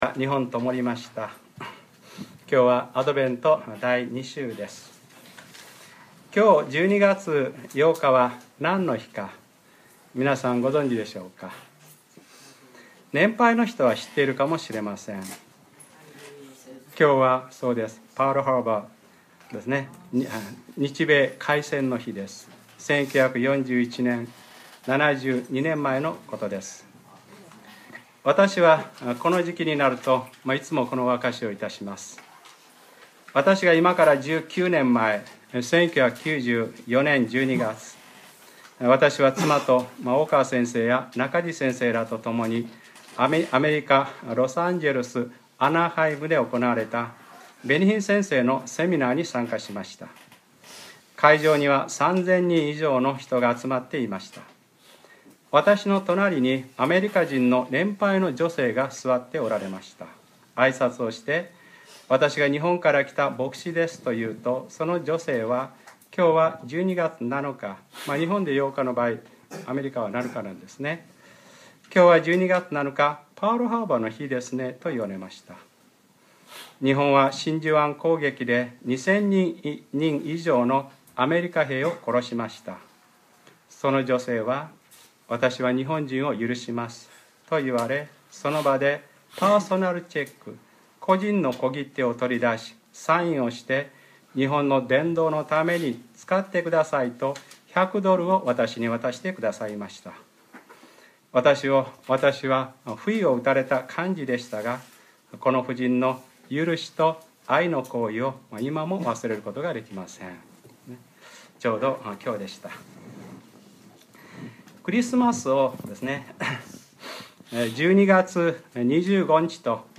2013年12月08日（日）礼拝説教 『神を恐れよ 神の命令を守れ』